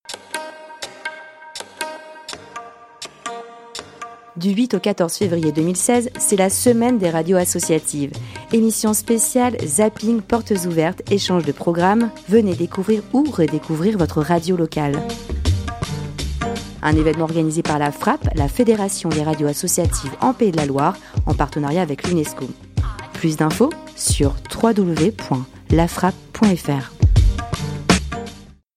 Bande-annonce | Semaine des Radios Associatives en Pays de la Loire